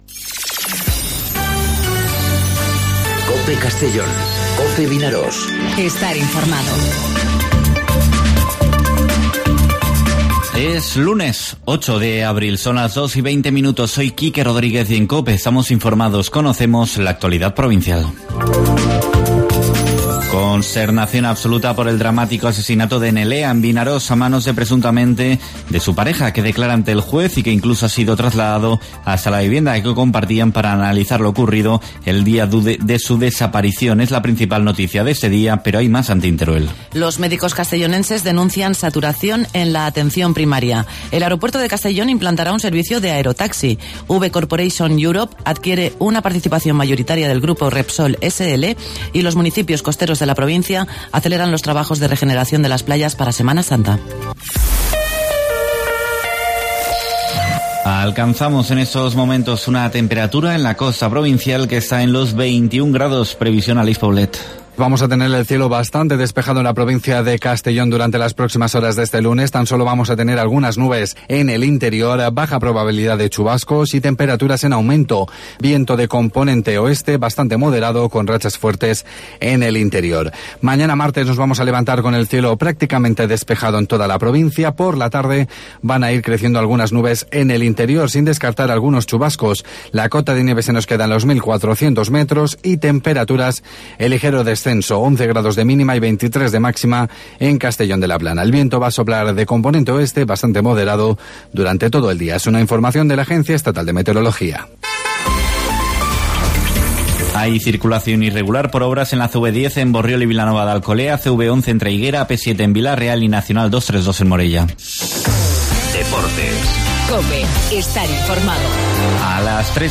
Informativo 'Mediodía COPE' en Castellón (08/04/2019)